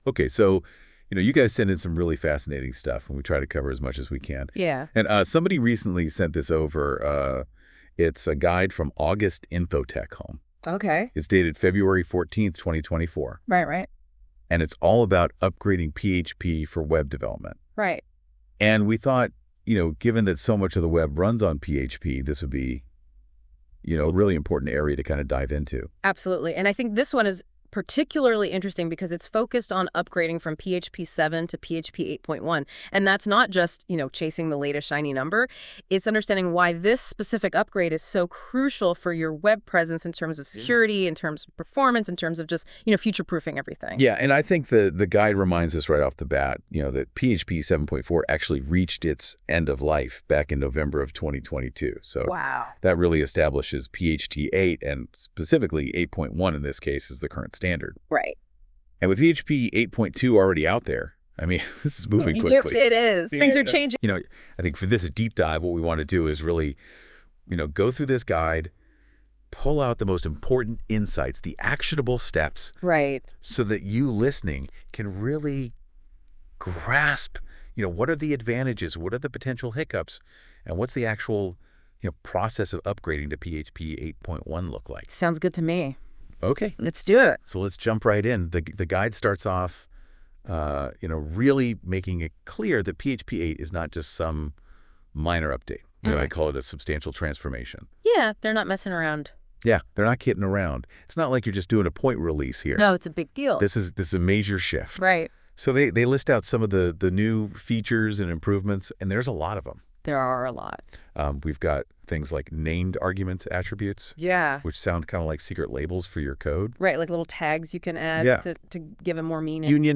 AI Podcast PHP 7 to 8.1 Upgrade: A Comprehensive Guide Play Episode Pause Episode Mute/Unmute Episode Rewind 10 Seconds 1x Fast Forward 10 seconds 00:00 / 00:14:10 Subscribe Share RSS Feed Share Link Embed